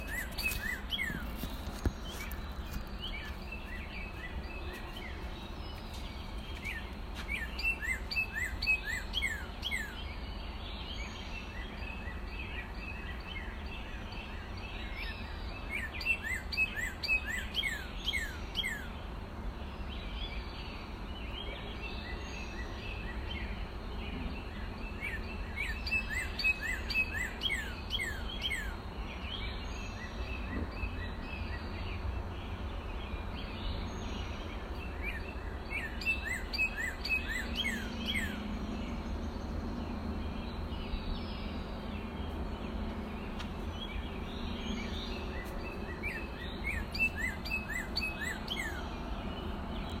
Morning at the Camden Longhouse
Bathing in the joyous music of the birds and the bugs is worth rising at such an early hour. A symphony of tweets, chirps, and clicks is supported by the staccato hammering of a woodpecker.